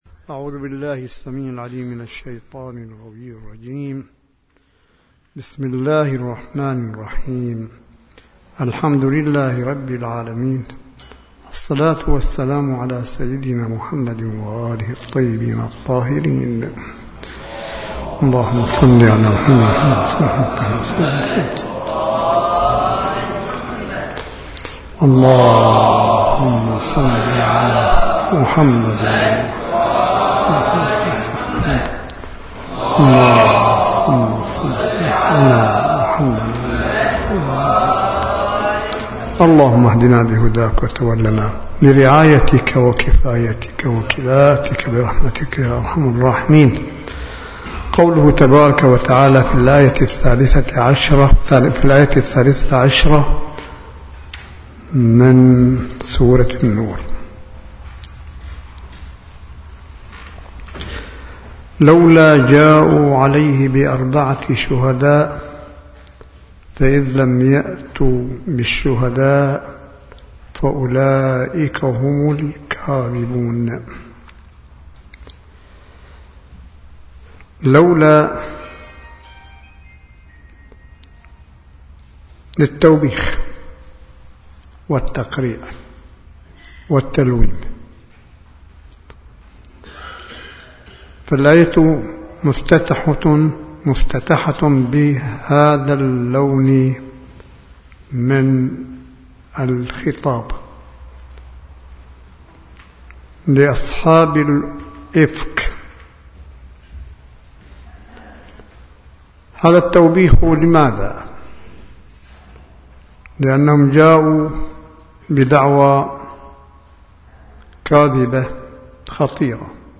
ملف صوتي للحديث القرآني الاسبوعي لسماحة آية الله الشيخ عيسى أحمد قاسم حفظه الله بجمعية التوعية الاسلامية – الإربعاء 30 مارس 2016م